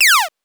8 bits Elements / laser shot
laser_shot_8.wav